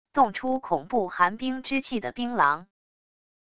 动出恐怖寒冰之气的冰狼wav音频